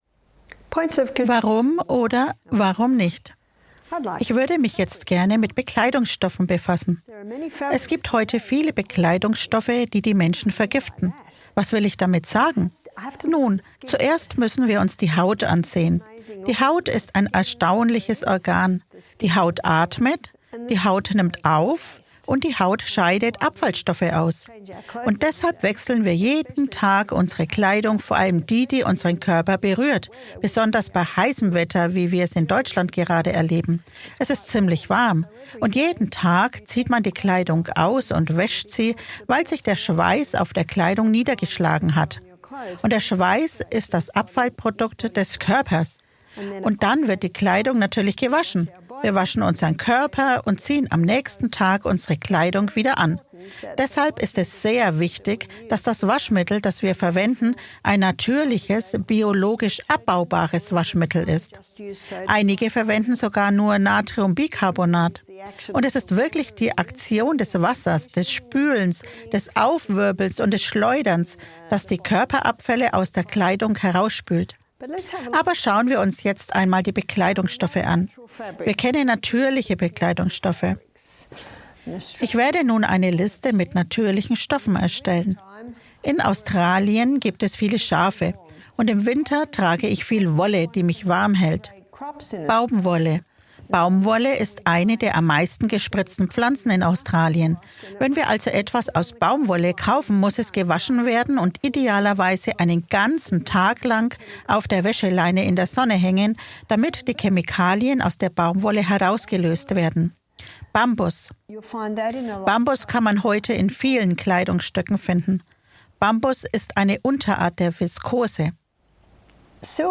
In diesem aufschlussreichen Vortrag wird erläutert, wie synthetische Materialien und Chemikalien in Textilien die Gesundheit beeinträchtigen können. Dabei stehen insbesondere die Warnungen zu BHs und Schlafkleidung im Fokus.